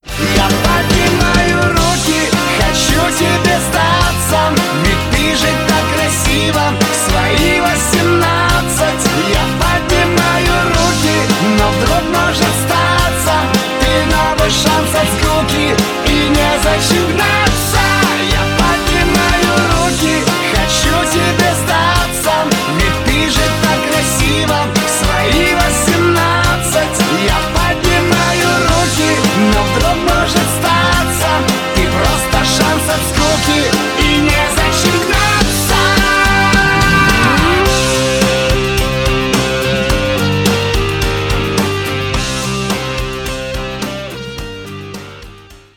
• Качество: 320, Stereo
эстрадные